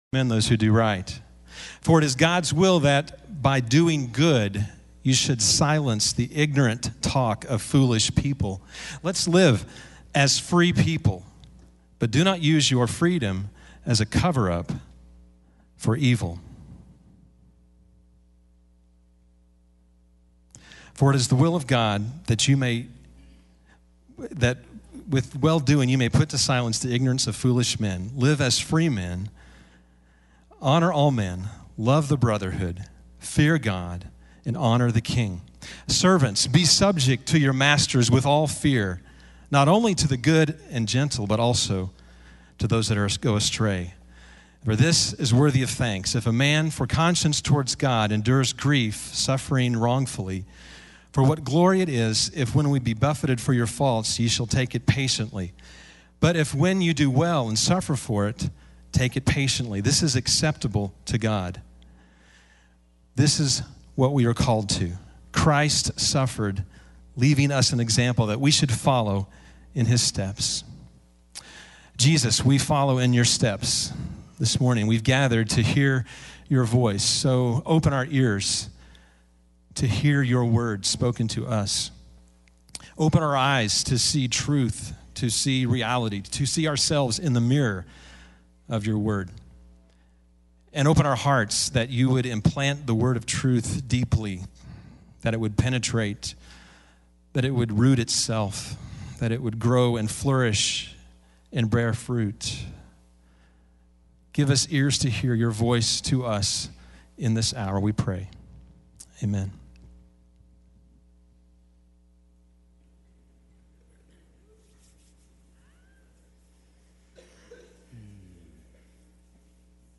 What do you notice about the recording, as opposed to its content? TITLE: Warning Texts: Jude (Jude) PLACE: Ozark Christian College (Joplin, MO) DESCRIPTION: We will never understand grace, freedom, or obedience until we understand: we are war! AUDIO: To listen to this sermon online, simply click this link.